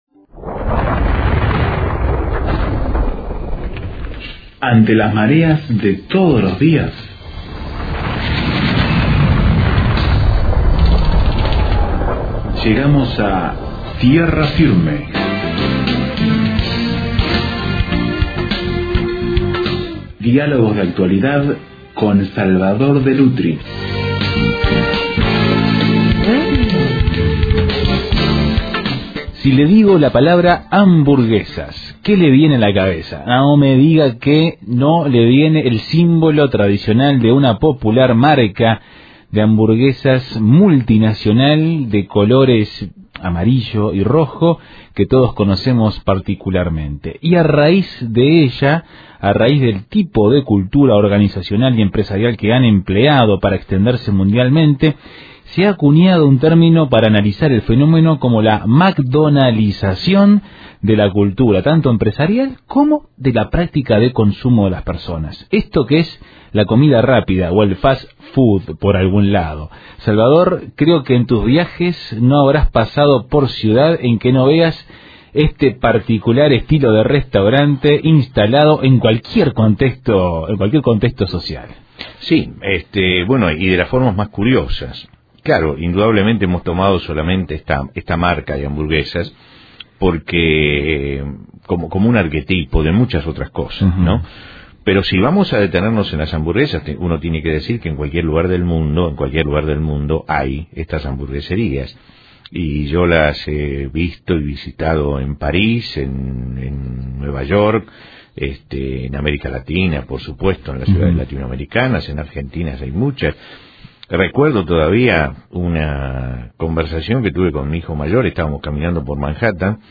A raíz del tipo de cultura organizacional y empresarial que han empleado para extenderse mundialmente, se ha acuñado un término para analizar este fenómeno: la McDonalización de la cultura, tanto empresarial como de la práctica de consumo de las personas. Analizamos este tema en una charla